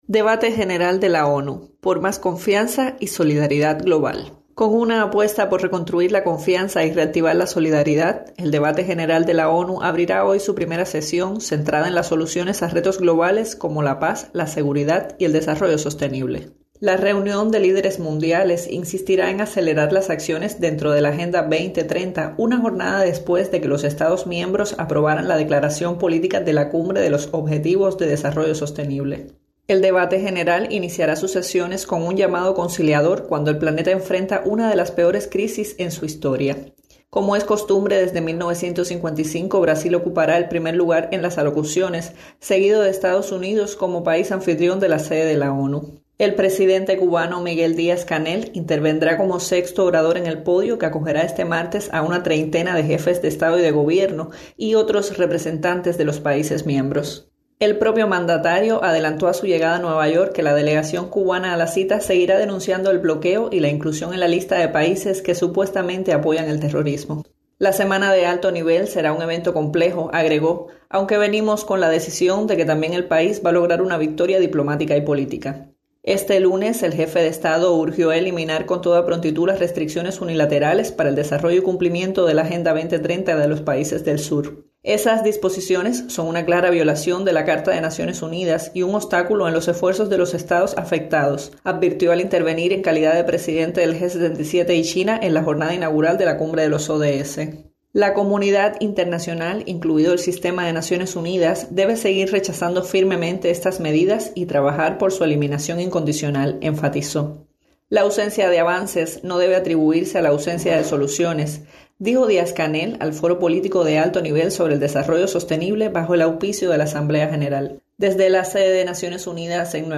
desde Naciones Unidas